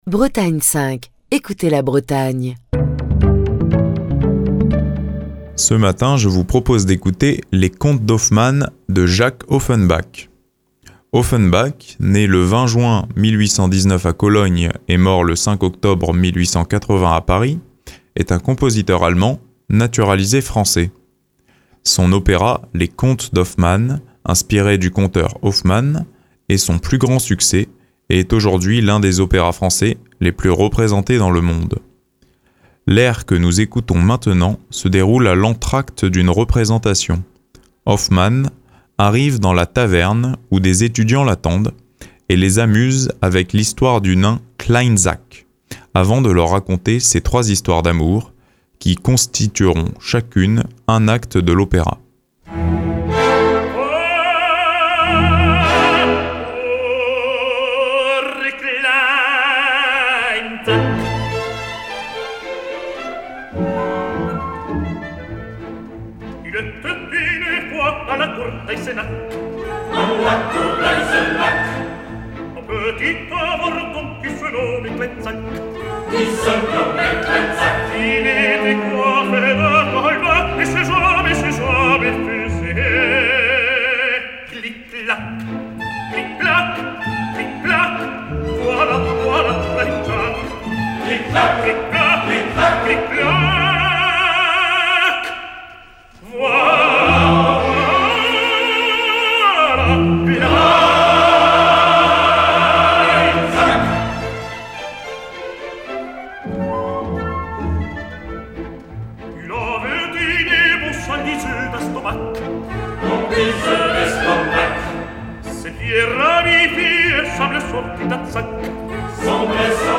Nous venons d'entendre "Va pour Kleinzach", un extrait de l'opéra Les Contes d'Hoffmann, interprété par le ténor Placido Domingo, pour qui ce rôle fut emblématique. Il était accompagné de l'Orchestre de la Suisse Romande sous la direction de Richard Bonynge.